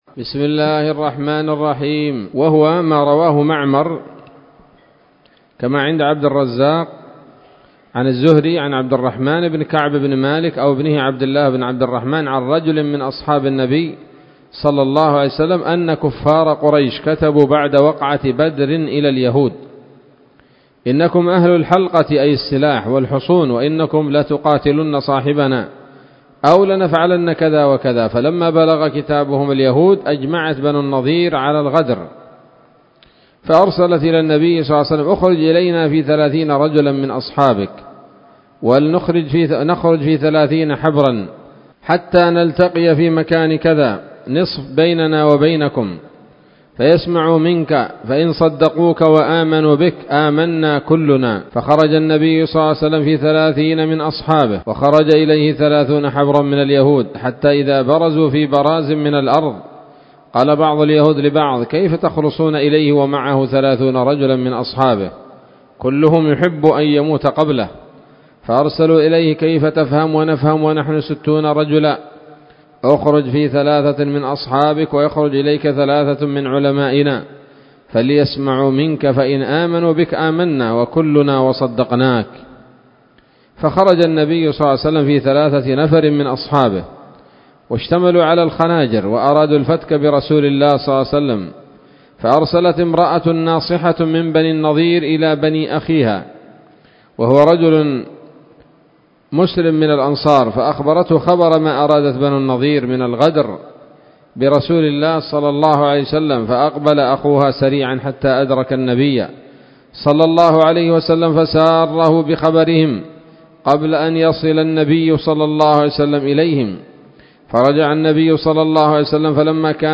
الدرس التاسع والثمانون بعد المائة من التعليق على كتاب السيرة النبوية لابن هشام